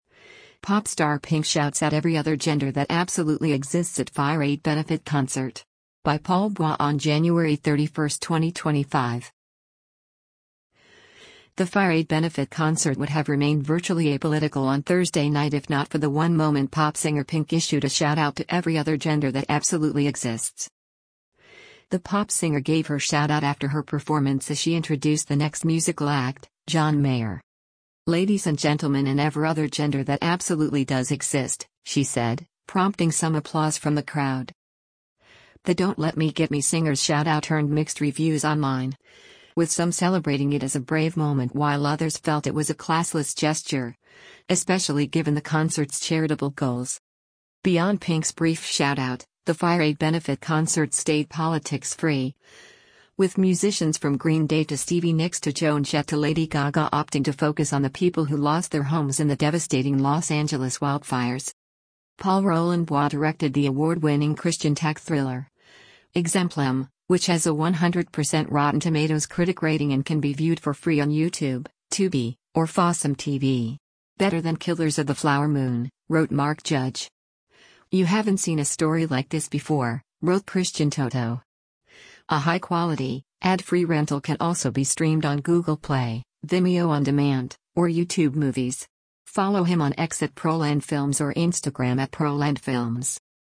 INGLEWOOD, CALIFORNIA - JANUARY 30: P!nk performs onstage during the FIREAID Benefit Conce
“Ladies and gentleman and ever other gender that ABSOLUTELY does exist,” she said, prompting some applause from the crowd.